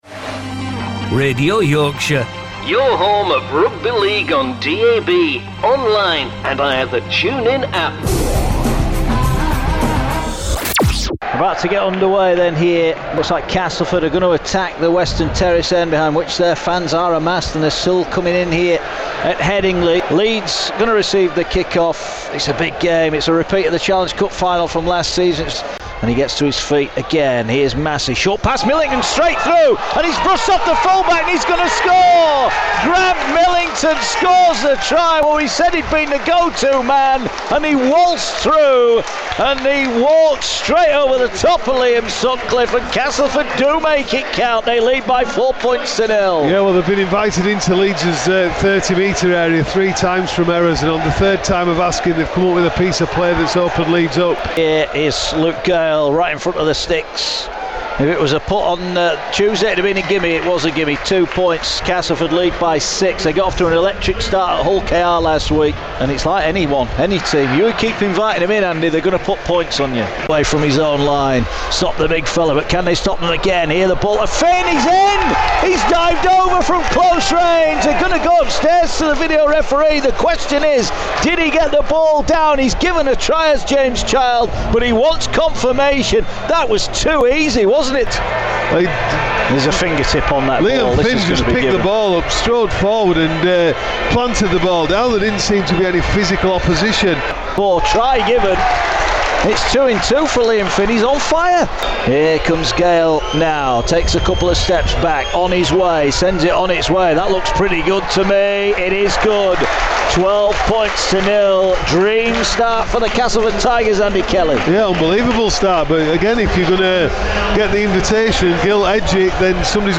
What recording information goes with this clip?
Highlights from a tight affair between Leeds Rhinos & Castleford Tigers at Headingley live on Radio Yorkshire.